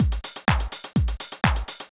R_S Beat_125.wav